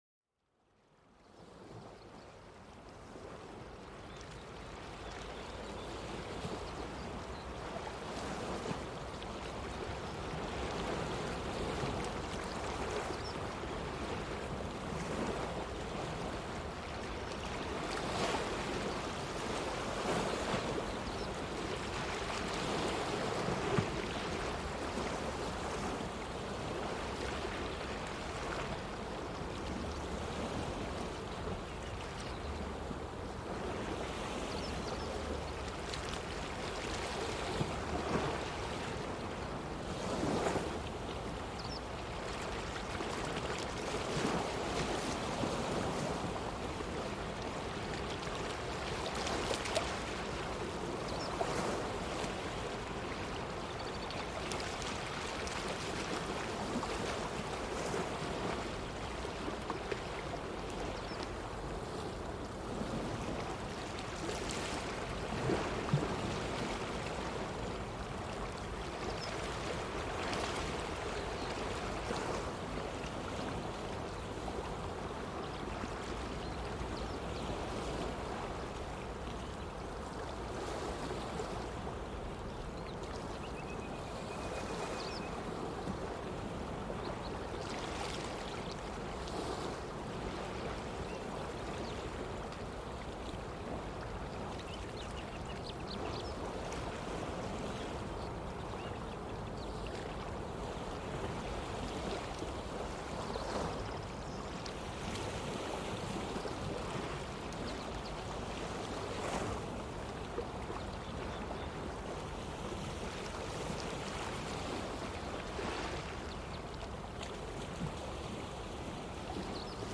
Sumérgete en el sonido relajante de las olas del océano. Deja que el ritmo natural de las olas calme tu mente.